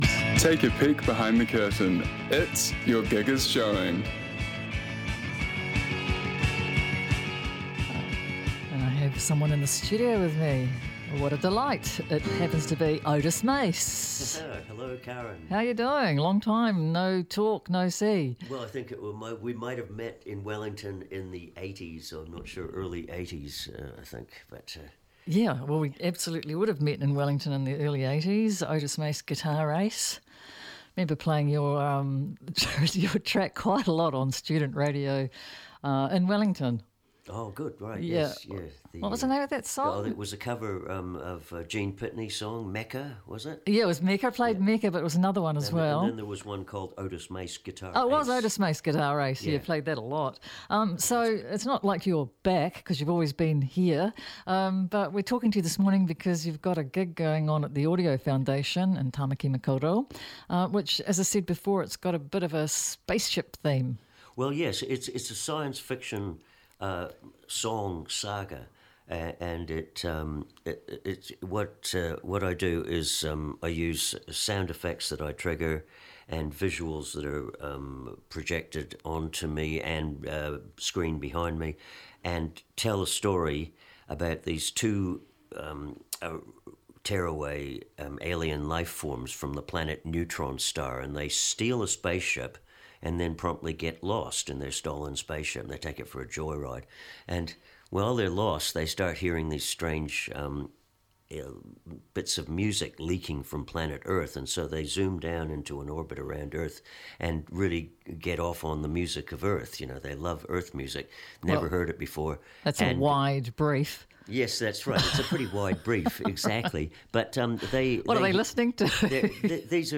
comes into the studio with his guitar